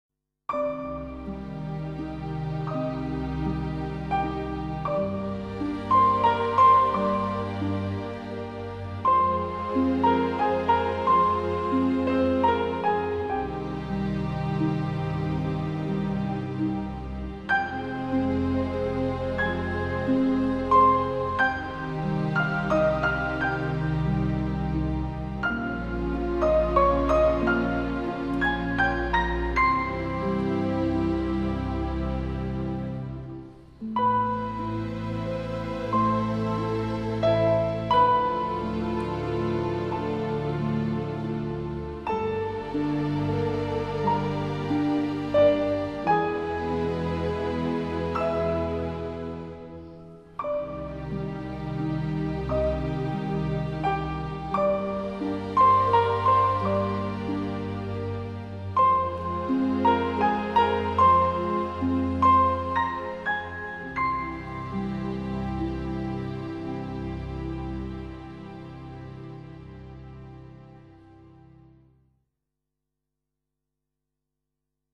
这一旋律具有一种典雅高贵的品质，节奏舒缓流畅、旋律优美动人